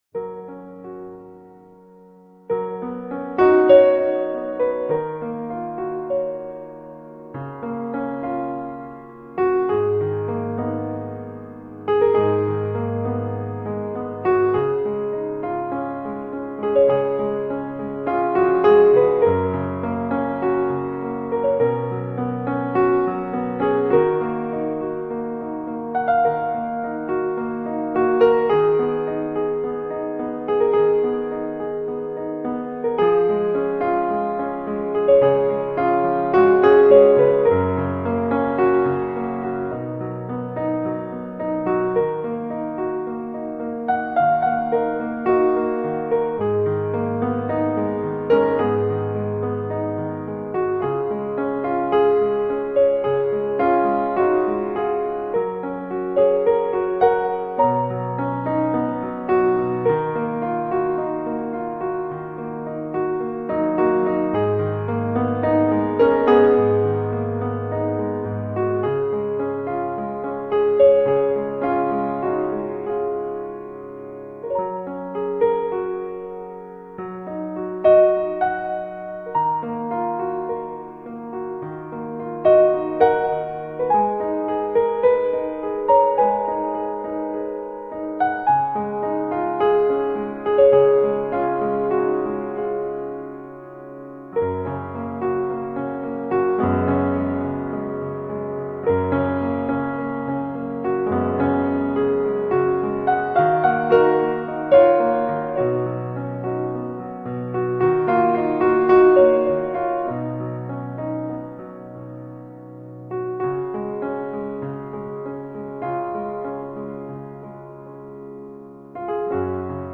【优美钢琴】
类型: New Age / Piano Solo